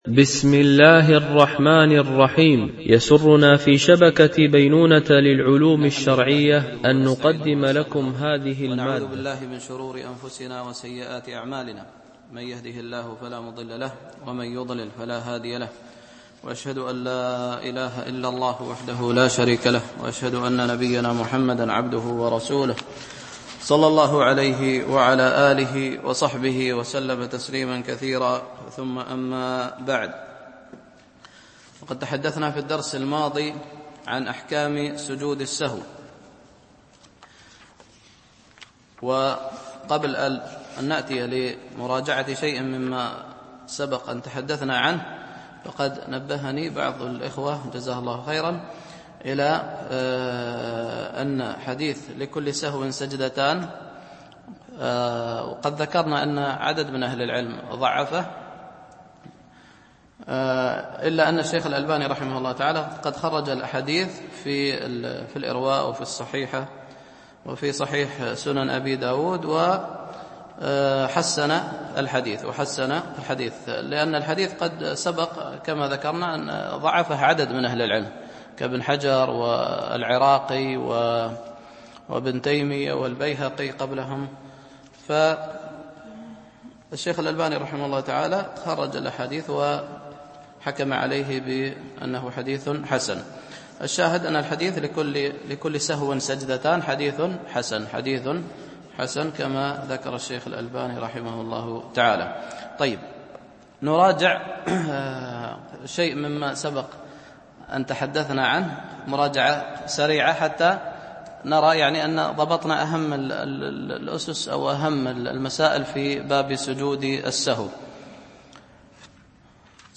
شرح عمدة الفقه ـ الدرس 31